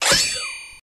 327Cry.wav